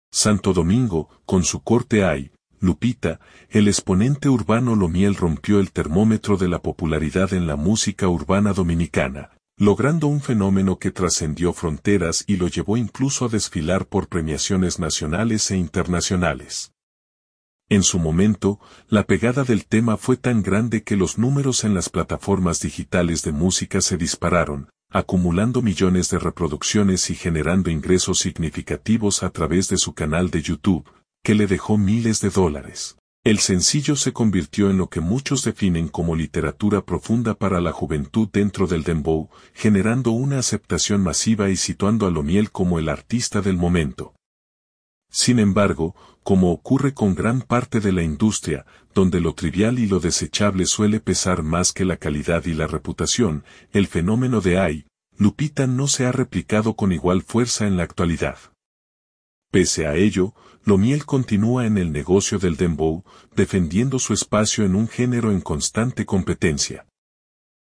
música urbana dominicana